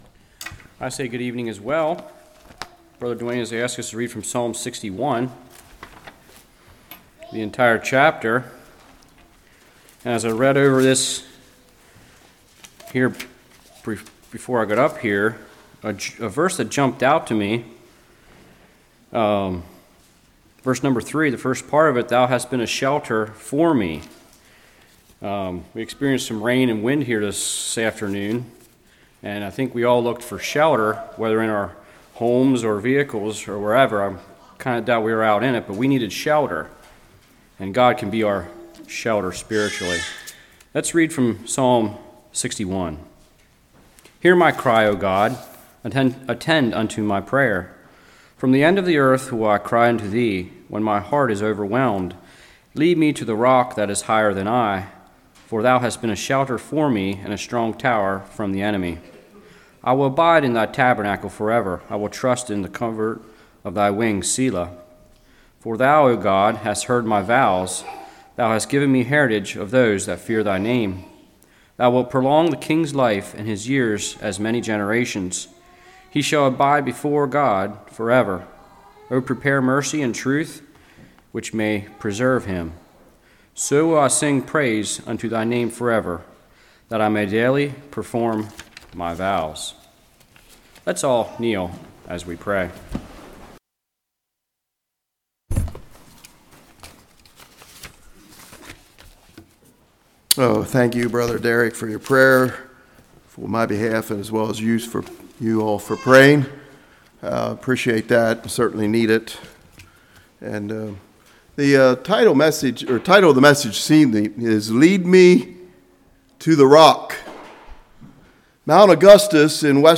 Psalm 61 Service Type: Evening Psalm 61 He’s The Rock of Our Salvation.